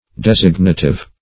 Search Result for " designative" : Wordnet 3.0 ADJECTIVE (1) 1. serving to designate ; The Collaborative International Dictionary of English v.0.48: Designative \Des"ig*na*tive\, a. [Cf. F. d['e]signatif.]